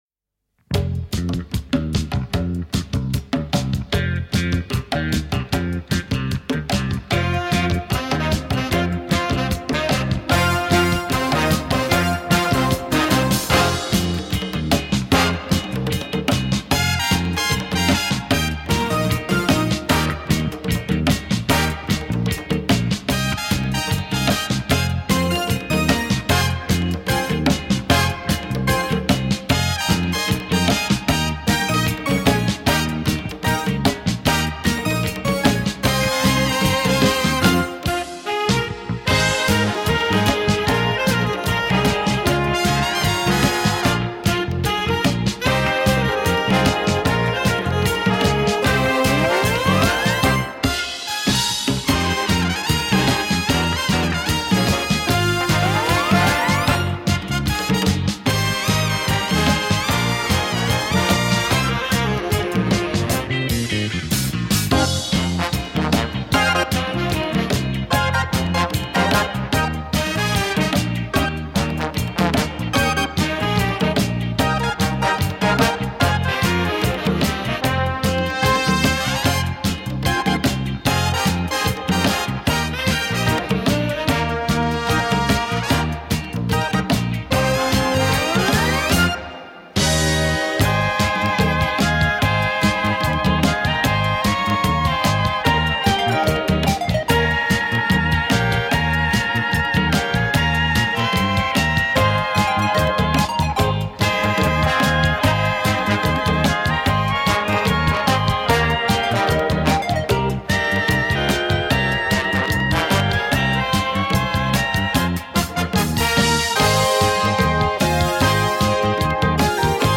Восточный танец